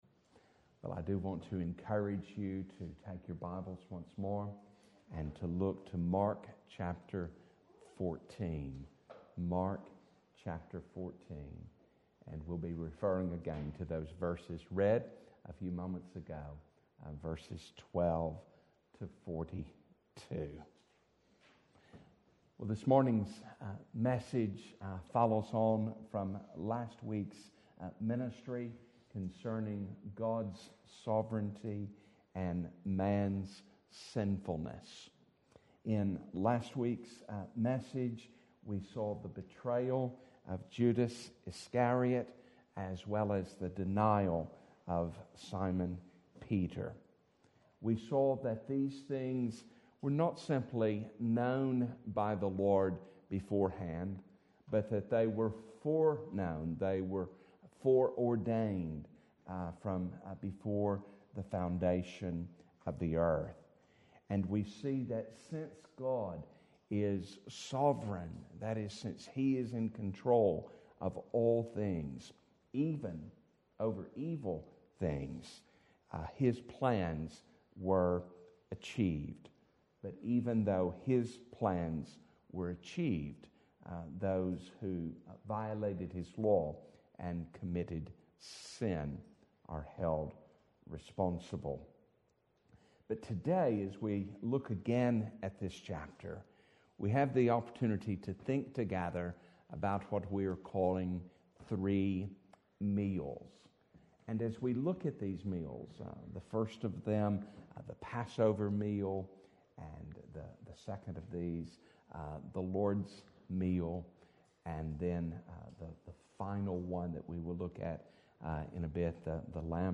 Our Serving and Suffering Saviour Passage: Mark 15:1-20 Service Type: Sunday Morning